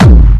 Kick 5.wav